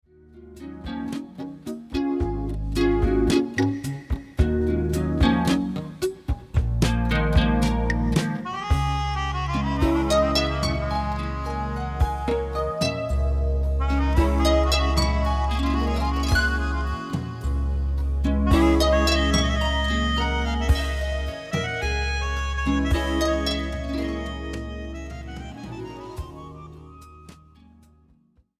percussion
drums